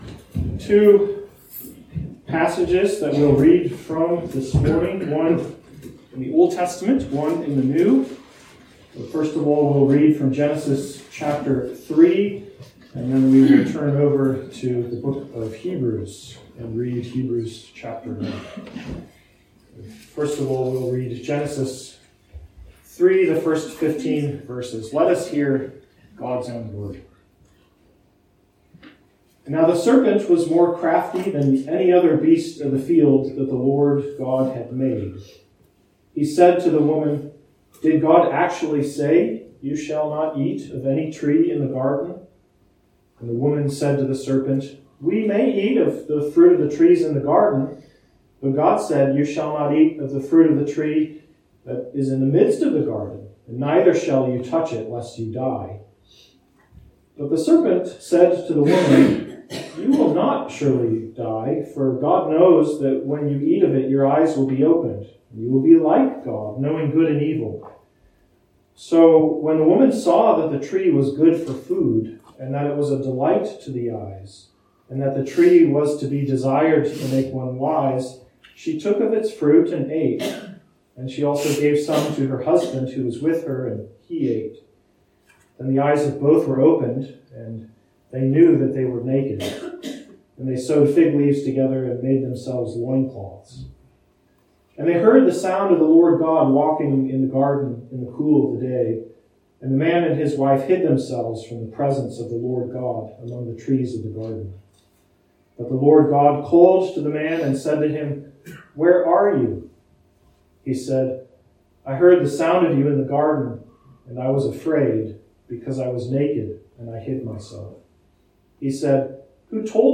A link to the video of the 11:00 am service, and an audio recording of the sermon.